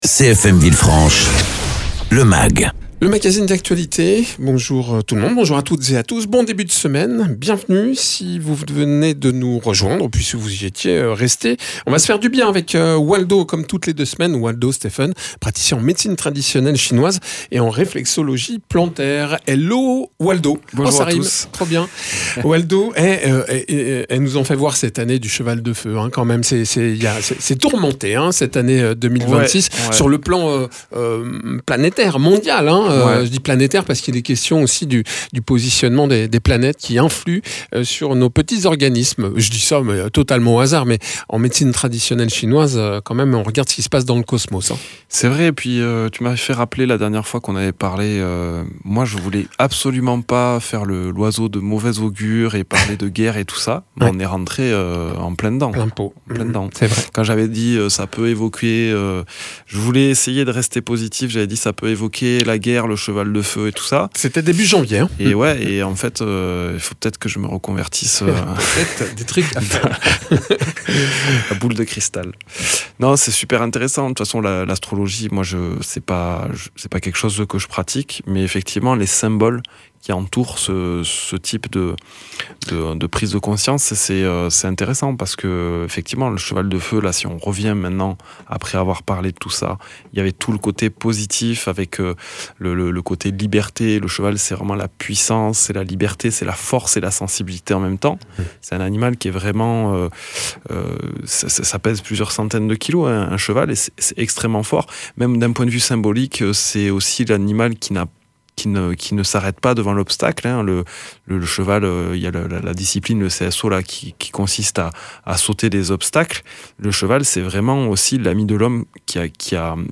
praticien en réflexologie plantaire et Médecine Traditionnelle Chinoise.